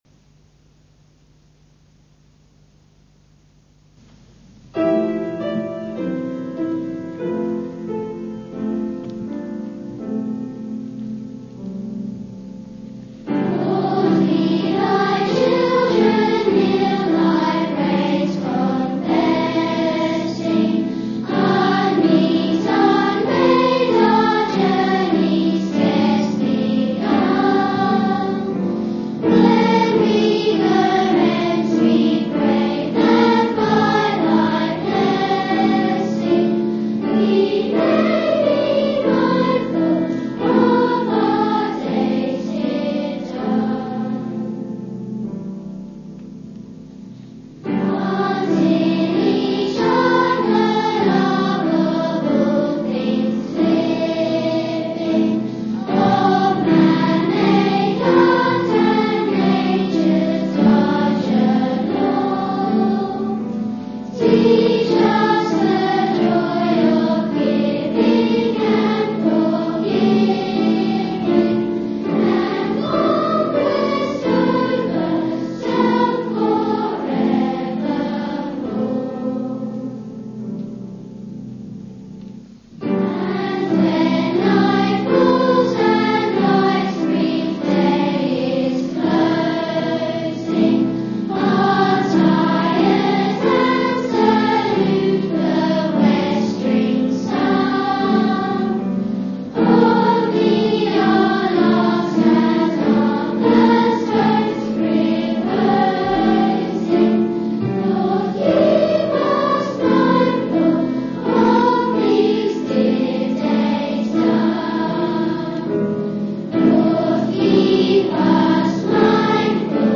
Sung by the School Choir of St Faith's at Ash (The Street, Ash, Canterbury)